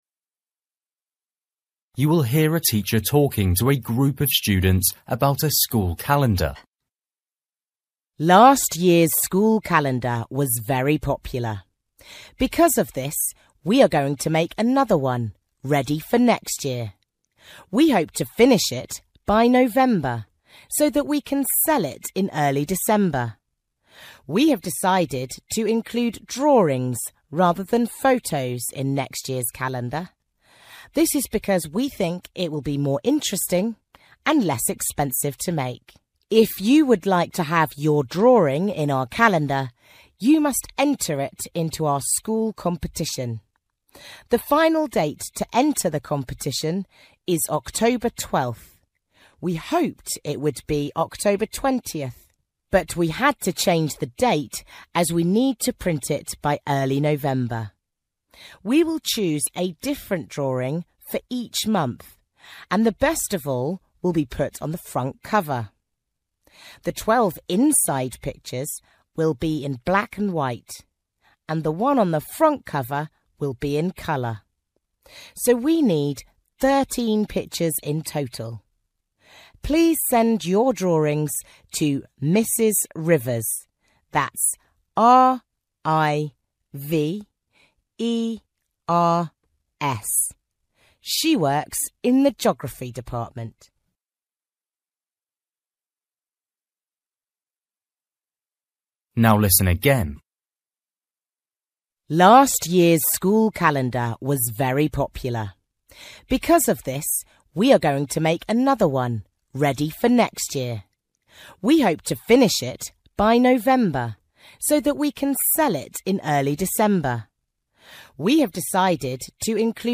You will hear a teacher talking to a group of students about a school calendar.